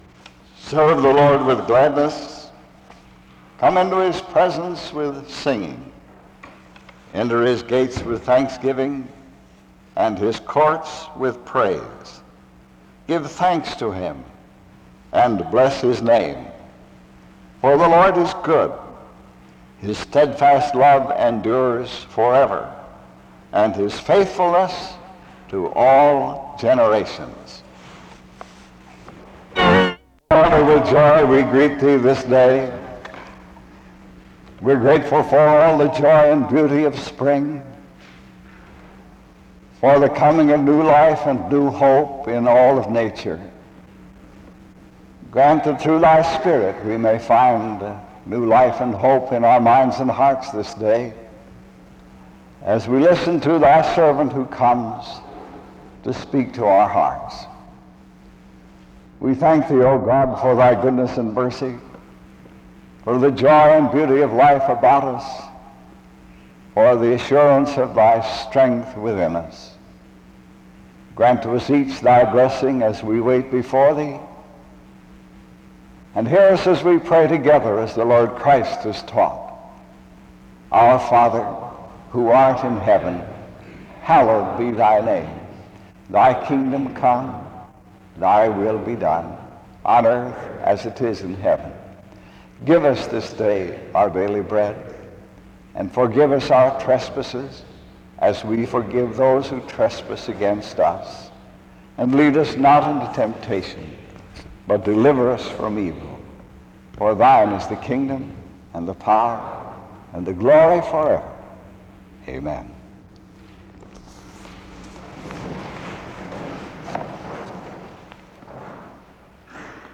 The service begins with a scripture reading and prayer (0:00-1:57).
The service closes in prayer (56:14-56:39).